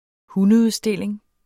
Udtale [ ˈhunə- ]